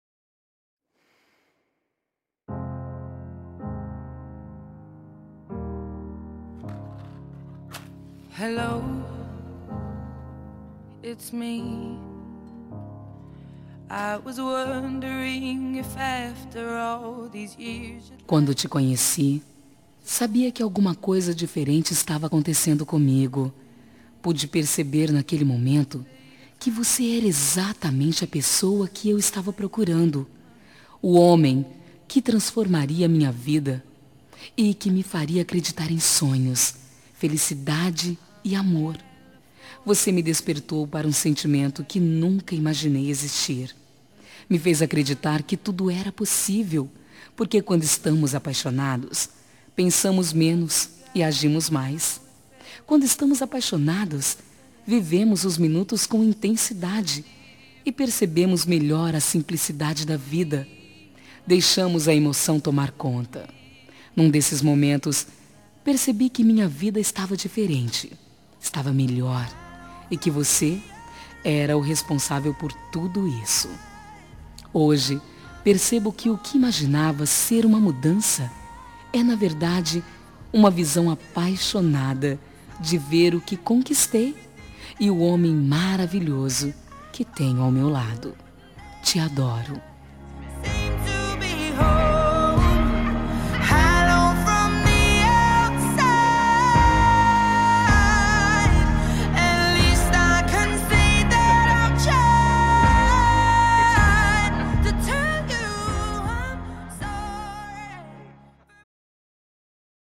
Telemensagem Início de Namoro – Voz Feminina – Cód: 740